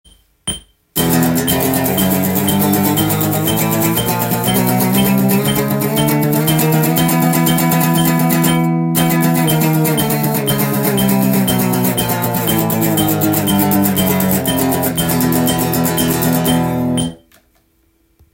【パワーコードストローク練習】オリジナルTAB譜
１６分音符
パワーコードを０フレットから半音階で１２フレットまで上がっていく
TAB譜　メトロノームのテンポは１２０です。
音符が沢山並ぶので速くストロークしなければいけません。